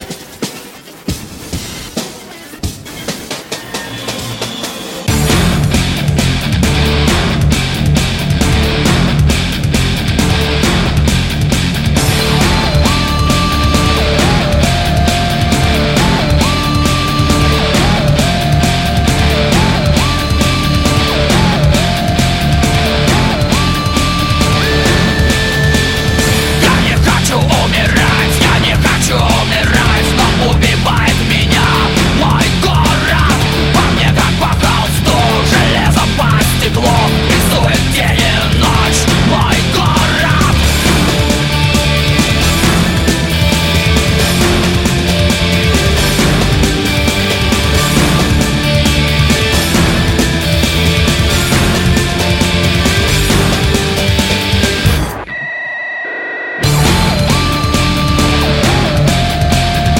Рок
А теперь вышел и студийный альбом!